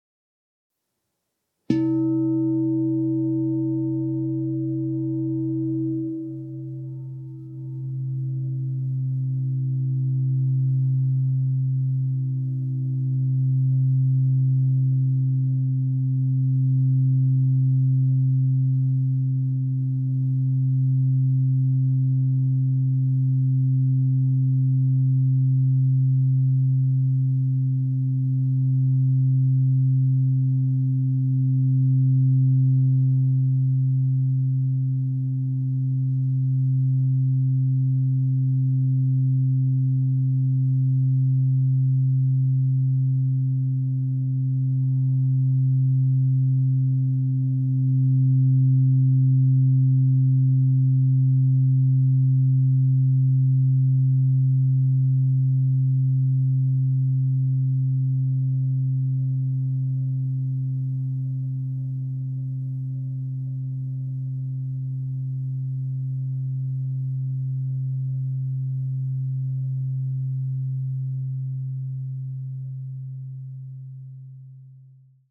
Meinl Sonic Energy 16" white-frosted Crystal Singing Bowl C3, 440 Hz, Wurzelchakra (CSBM16C3)
Produktinformationen "Meinl Sonic Energy 16" white-frosted Crystal Singing Bowl C3, 440 Hz, Wurzelchakra (CSBM16C3)" Die weiß-matten Meinl Sonic Energy Crystal Singing Bowls aus hochreinem Quarz schaffen durch ihren Klang und ihr Design eine sehr angenehme Atmosphäre.